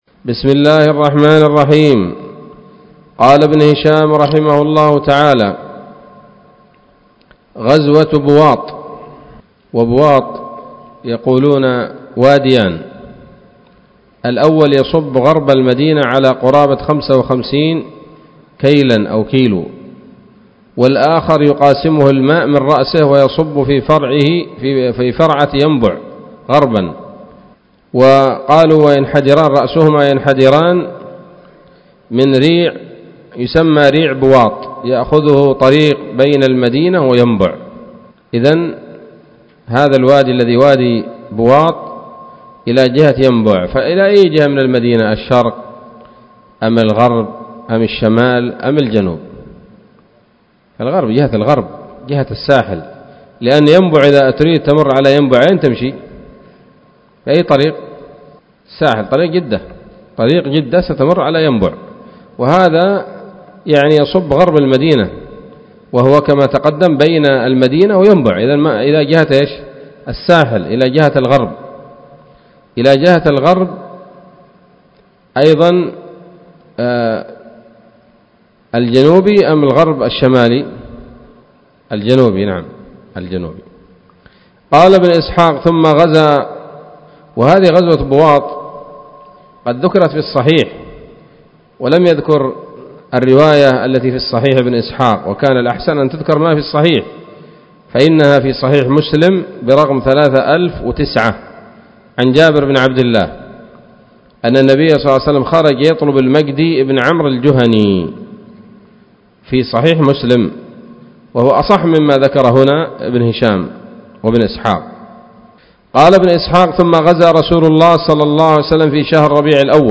الدرس الخامس بعد المائة من التعليق على كتاب السيرة النبوية لابن هشام